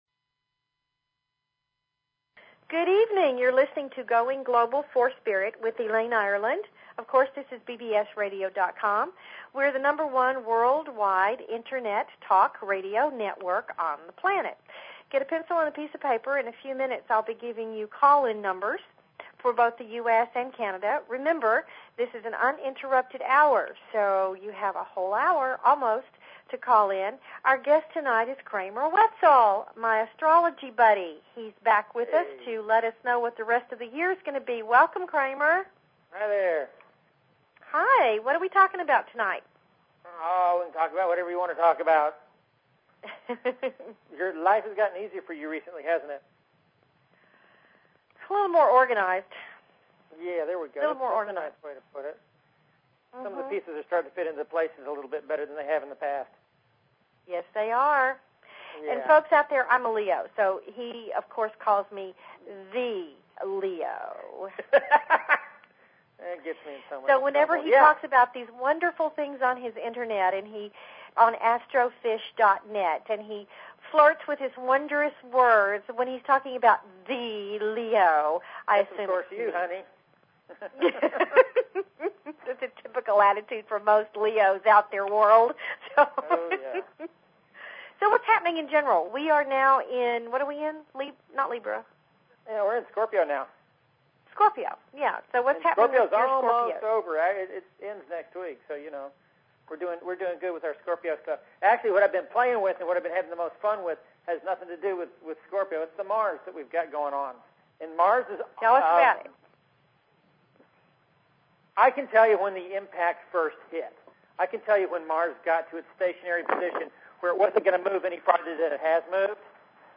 Talk Show Episode, Audio Podcast, Going_Global_for_Spirit and Courtesy of BBS Radio on , show guests , about , categorized as
SCORPIO, MARS AND A TINY LOOK AT 2008 IS PLANNED FOR THIS EVENING. CALL IN WITH YOUR QUESTIONS!